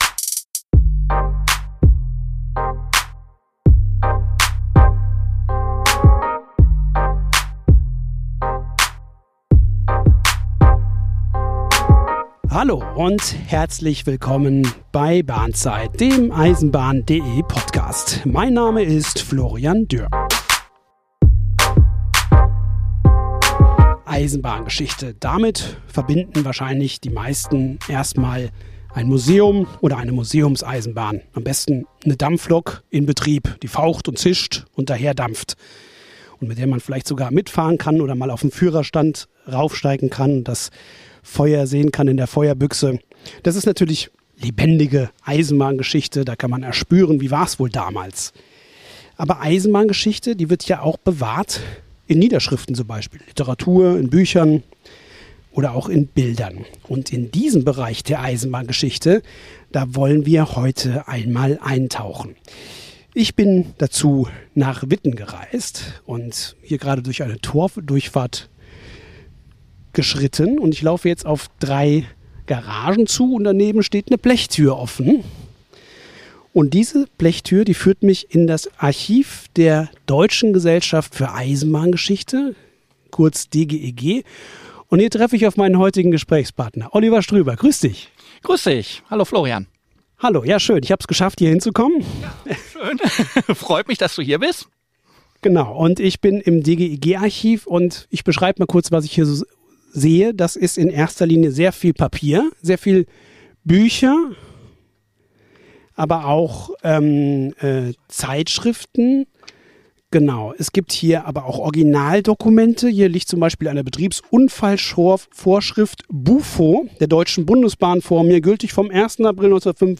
Zu Besuch im DGEG-Archiv ~ Bahnzeit Podcast